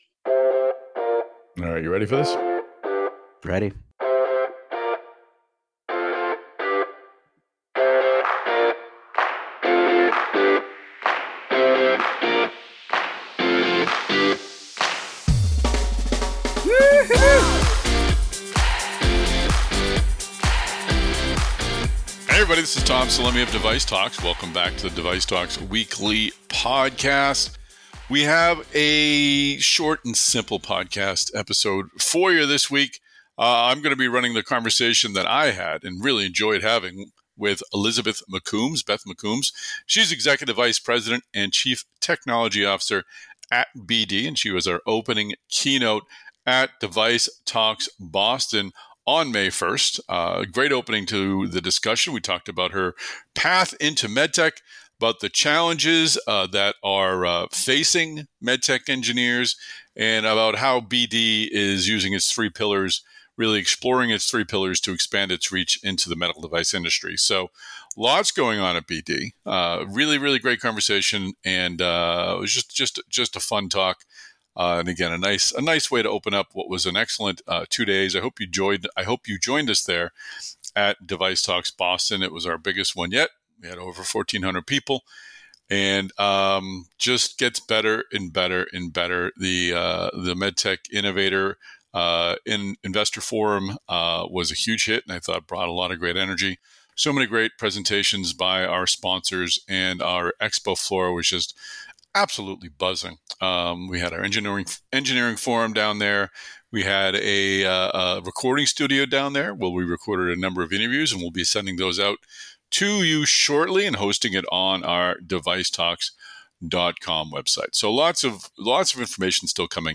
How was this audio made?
This is a replay of the opening keynote interview at DeviceTalks Boston.